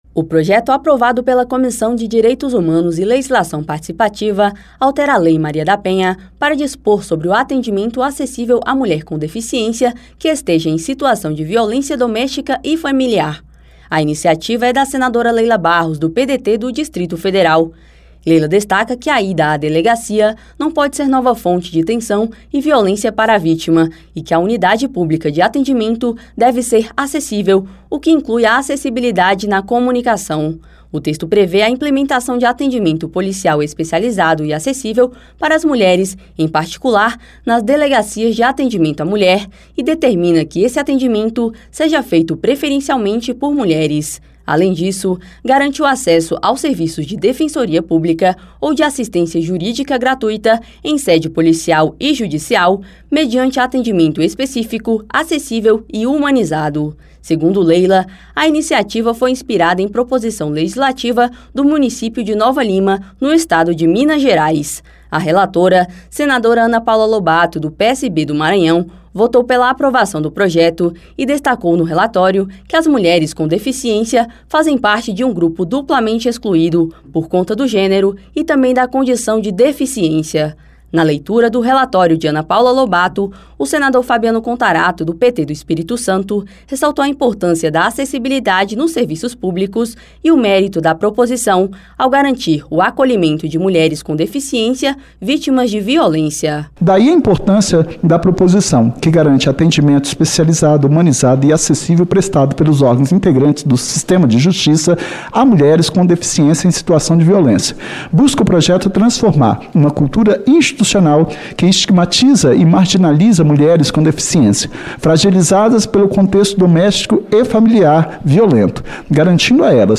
Na leitura do relatório da senadora Ana Paula Lobato (PSB-MA), o senador Fabiano Contarato (PT-ES) ressaltou o mérito da proposição e a importância da acessibilidade nos serviços públicos.